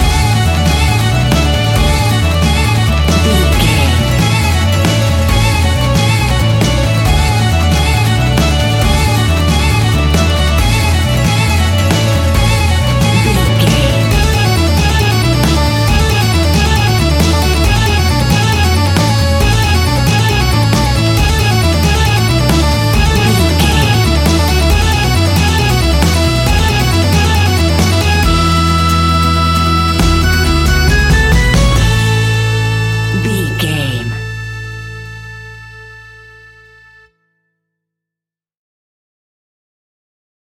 Ionian/Major
acoustic guitar
mandolin
lapsteel
double bass
accordion